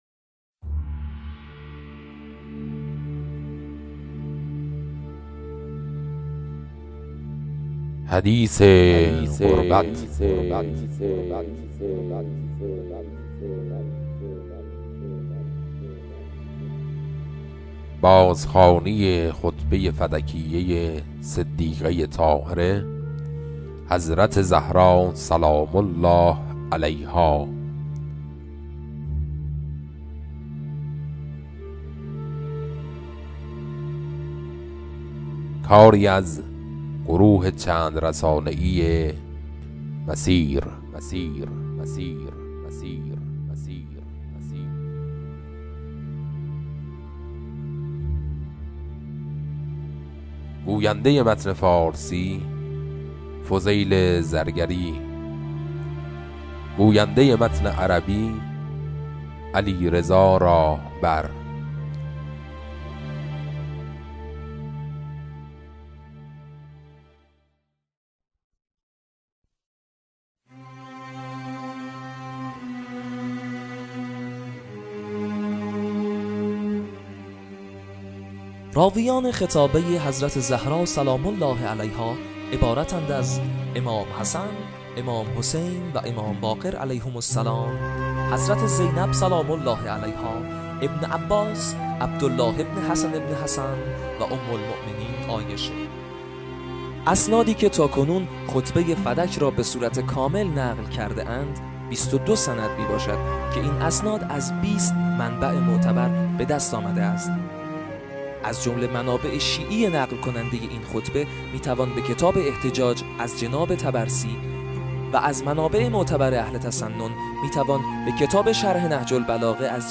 بازخوانی خطبه فدکیه حضرت زهرا (س)